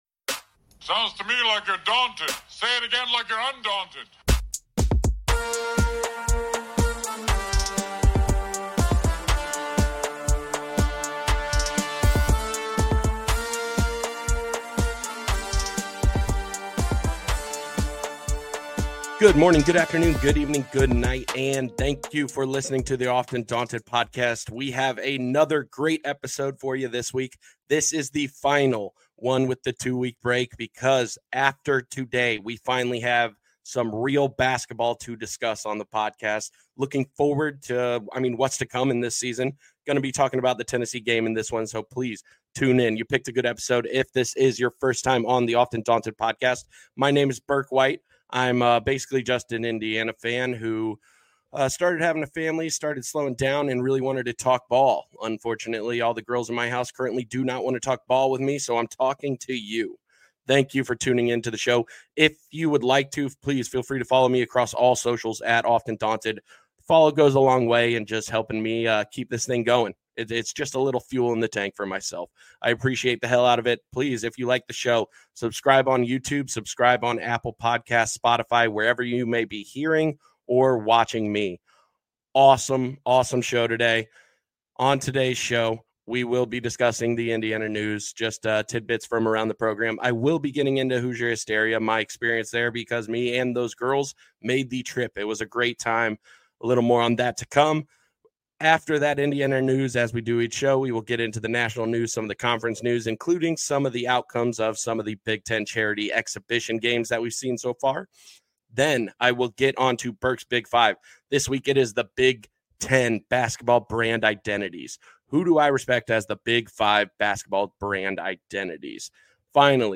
Thank you so much for giving the show a chance, and I know the audio isn't up to par with where the audio only episodes were.